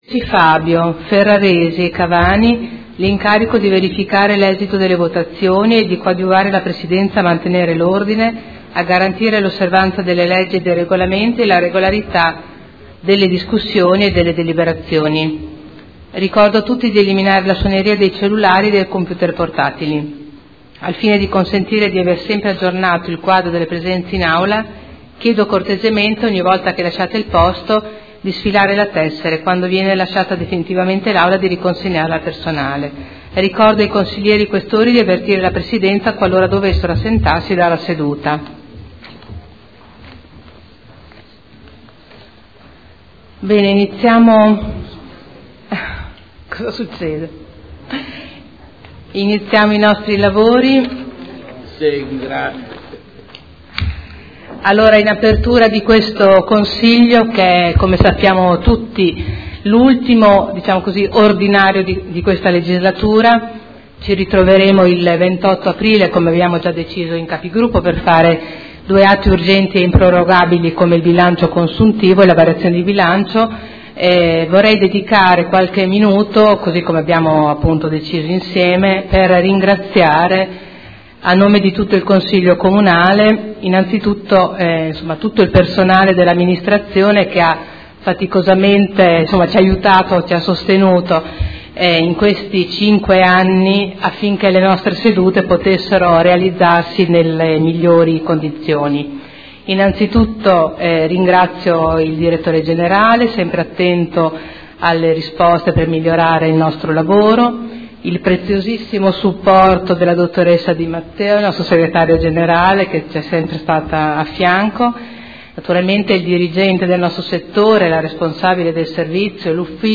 Seduta del 7 aprile. Apertura del Consiglio Comunale, ringraziamenti di fine consiliatura